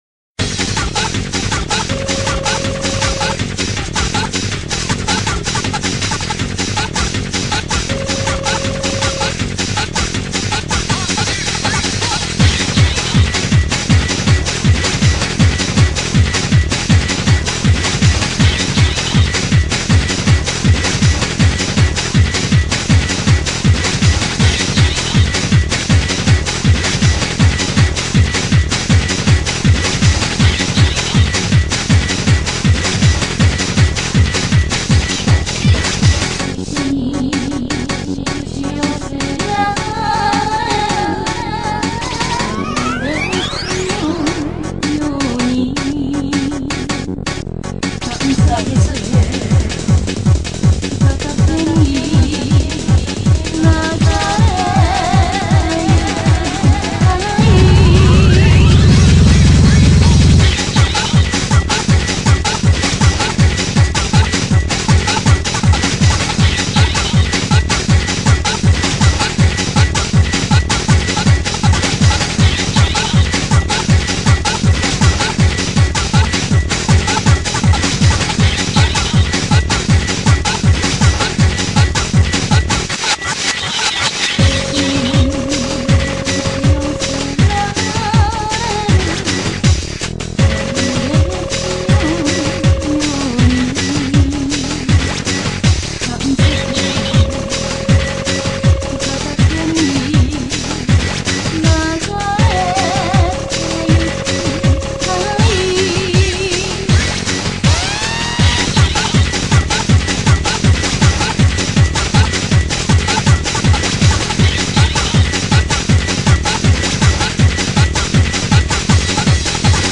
BPM80-160
Audio QualityPerfect (High Quality)